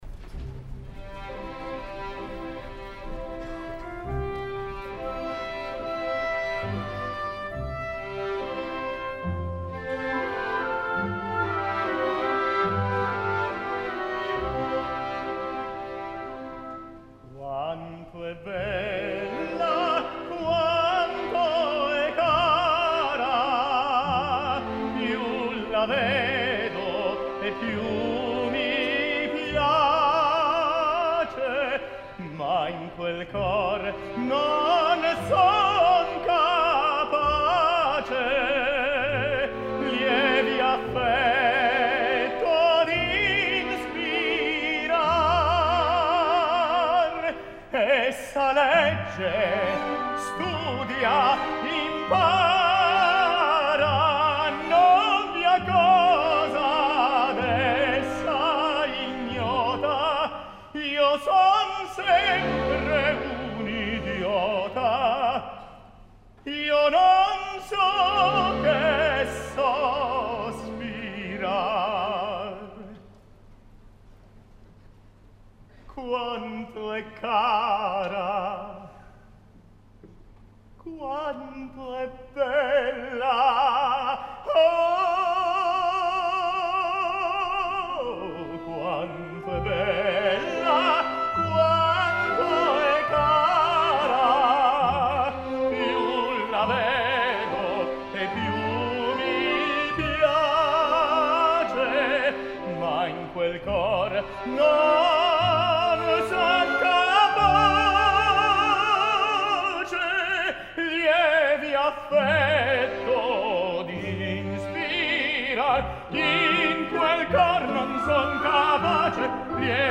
Roberto Alagna - Nemorino a la Opéra National de Paris (2015) Roberto Alagna - Nemorino a la Opéra National de Paris (2015)